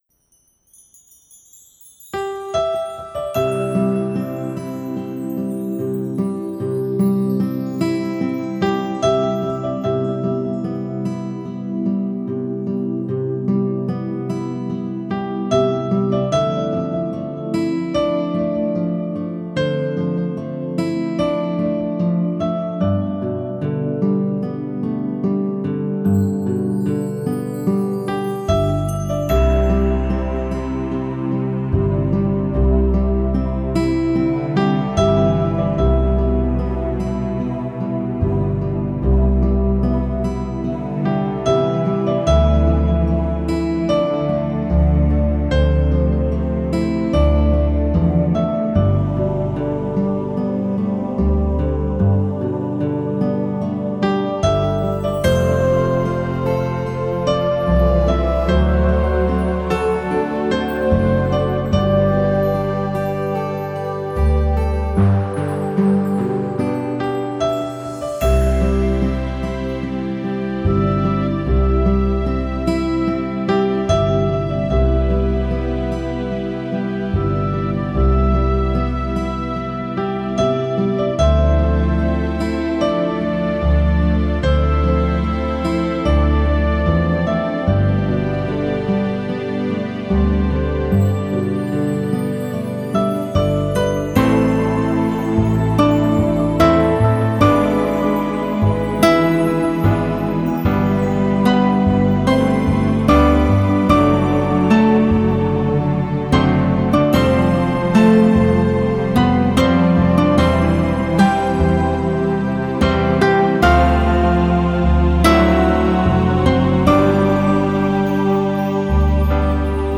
以如梦似幻的清新自然音乐
它的音乐以宽广的音场，逼真的音效，通透的音质而风靡全国